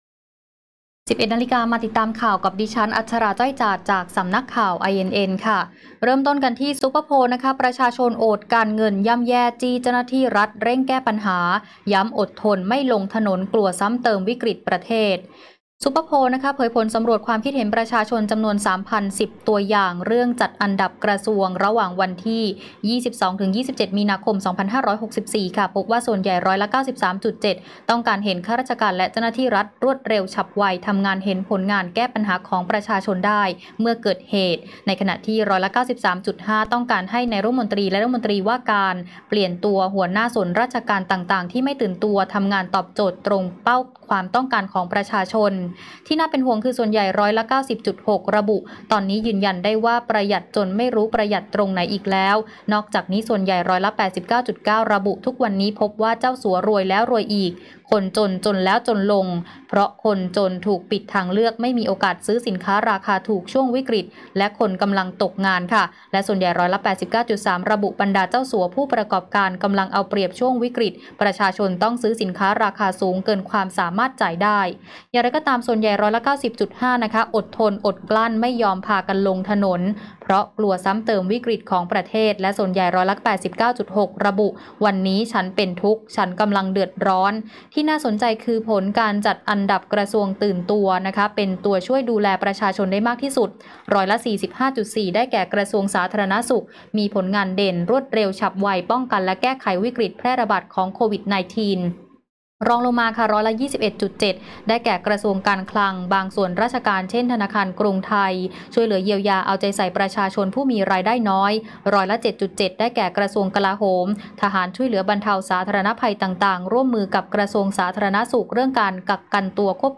ข่าวต้นชั่วโมง 11.00 น.